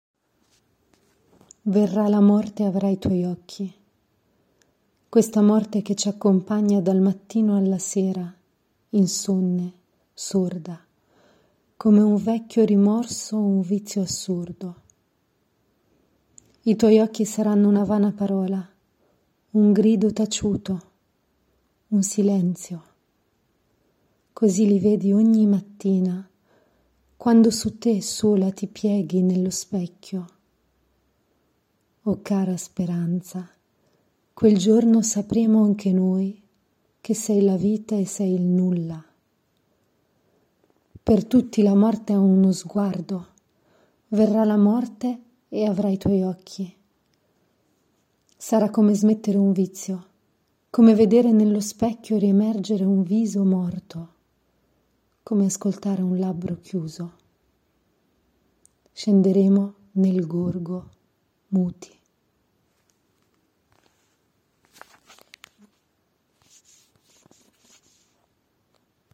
doublage voix